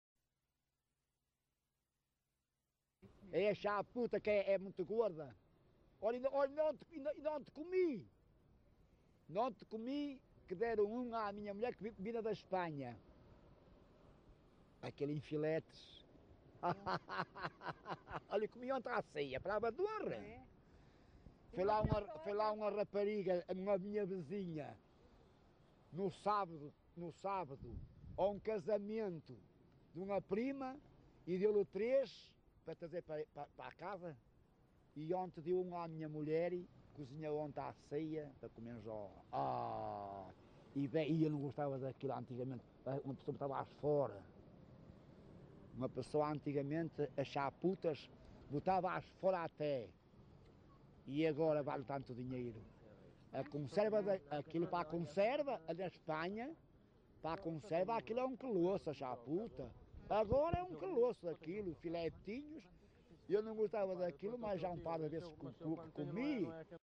LocalidadeVila Praia de Âncora (Caminha, Viana do Castelo)